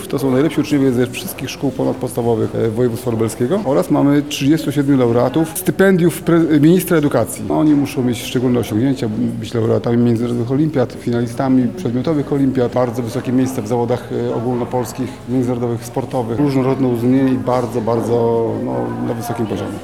O szczegółach mówi Lubelski Kurator Oświaty, Tomasz Szabłowski: